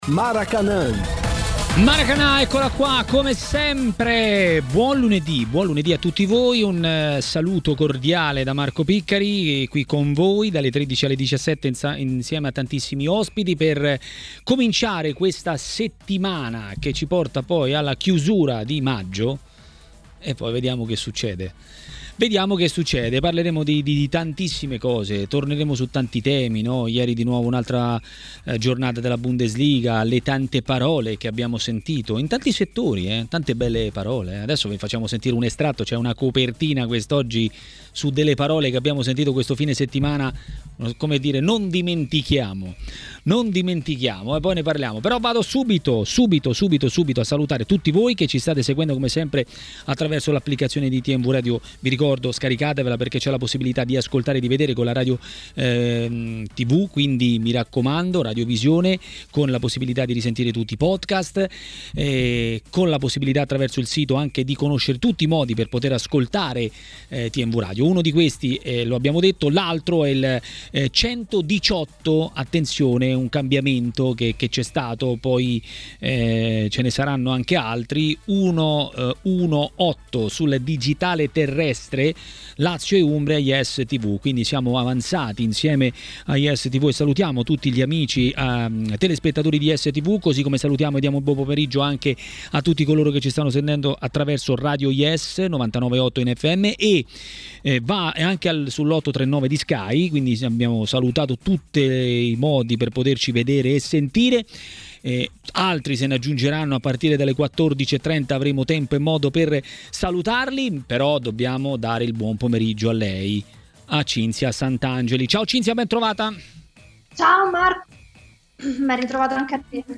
Presentazione di Maracanà conduce